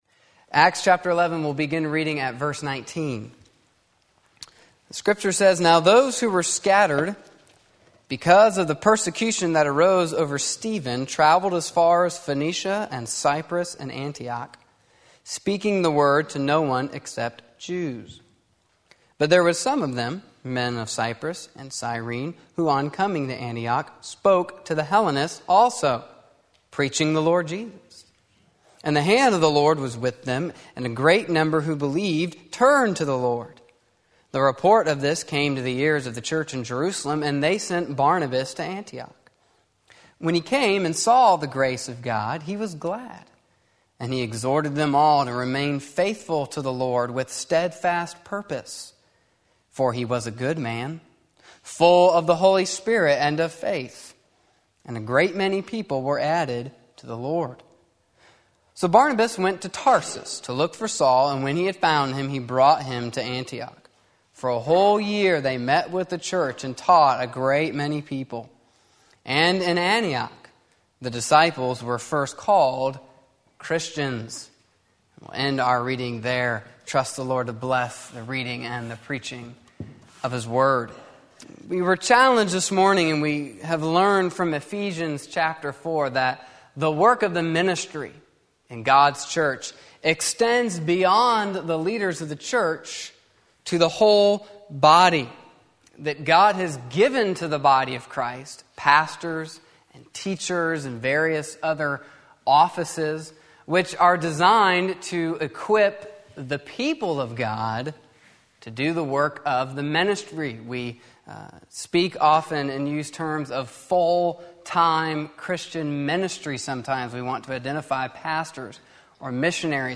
The People that God Uses in the Church Acts 11:19-26 Sunday Afternoon Service